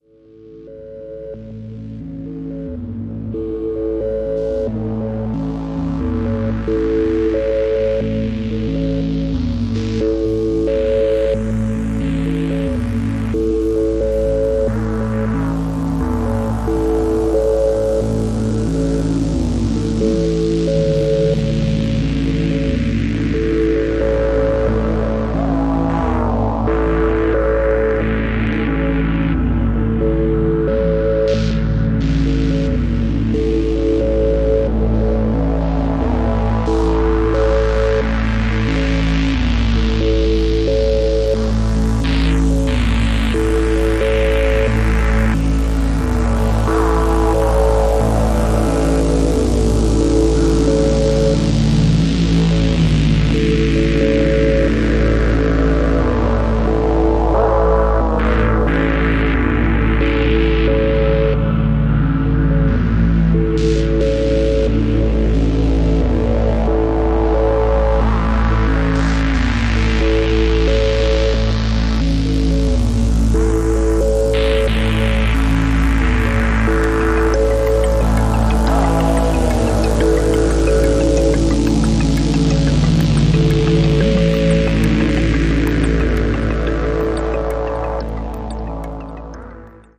Electronic music composer
purely synth based tracks